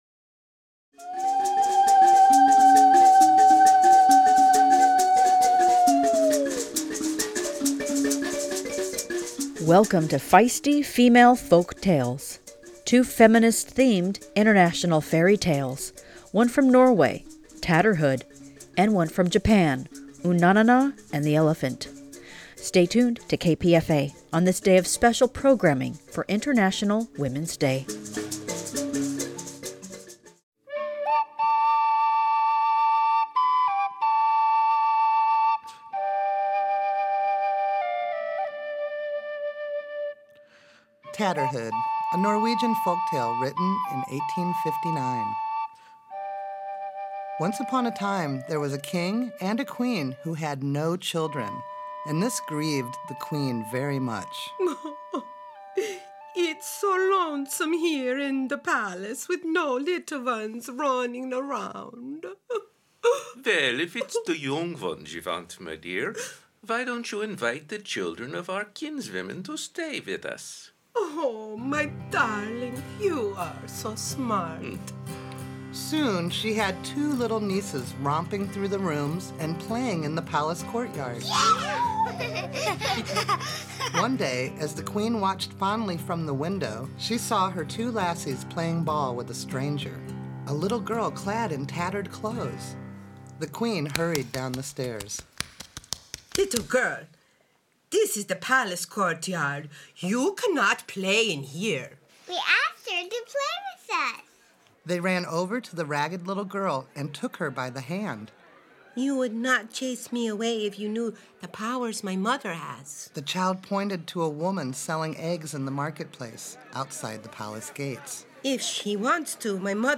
features original music